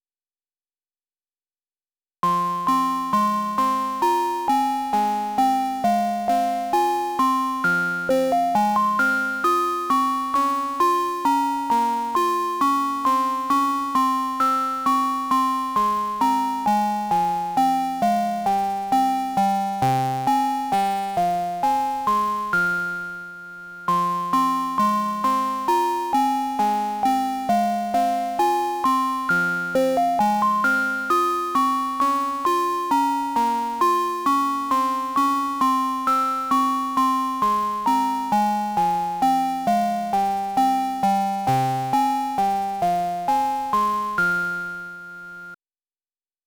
― 電子音シミュレーション用音源データ
P1S8902は矩形波、正弦波、鋸波、ノイズ音の4種類の波形を収録した電子音シミュレーション用音源データです。
波形は全て独立した音階を発音することができ、発音音量カーブ(エンベロープ)は3種類が標準で設定されています。